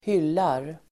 Uttal: [²h'yl:ar]